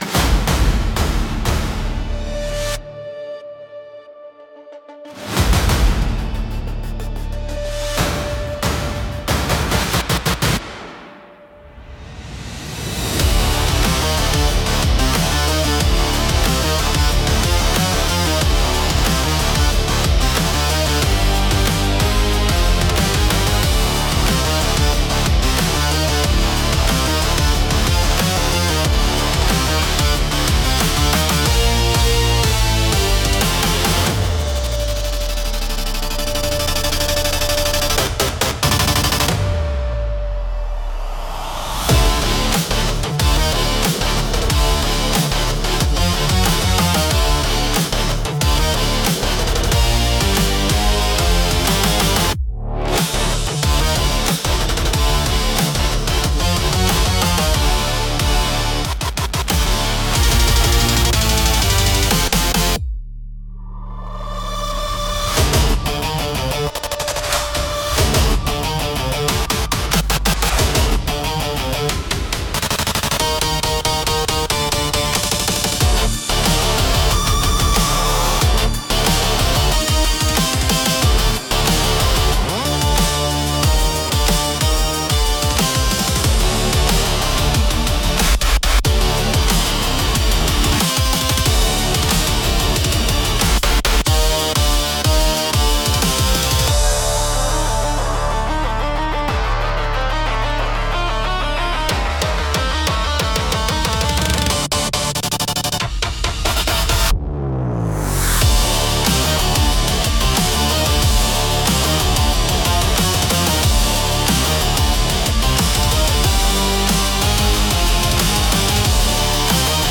激しいリズムと力強い音色で、緊張感や切迫した状況を鋭く表現します。